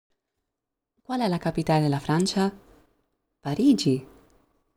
Infatti, quando chiediamo qualcosa, l’ultima sillaba dell’ultima parola che diremo sarà più acuta della precedente, mentre il contrario è vero quando terminiamo una frase affermativa.
Nell’audio che segue sentirete la domanda e la risposta mostrata nel grafico.
Intonazione-domanda-e-risposta.mp3